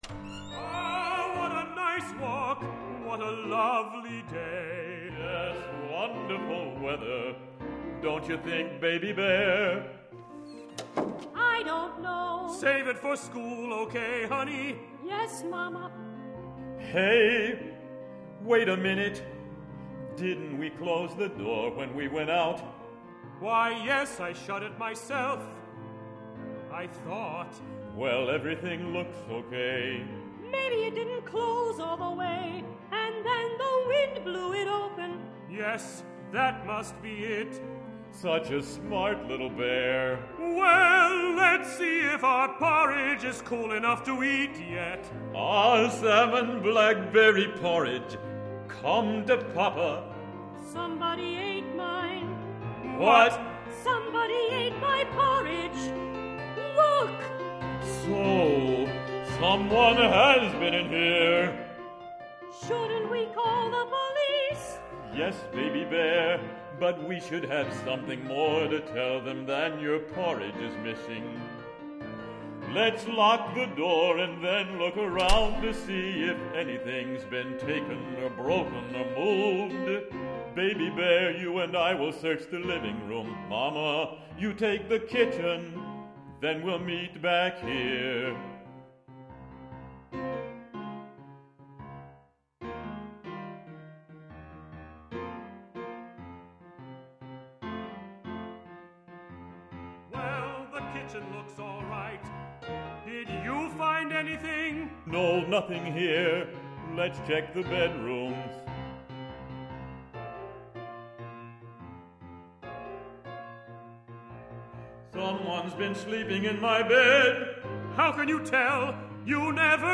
This opera for children is about tolerance for being different.